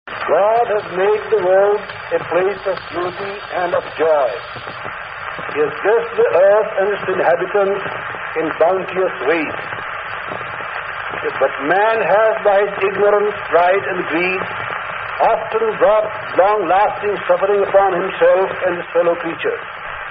MALAVIYAJI'S VOICE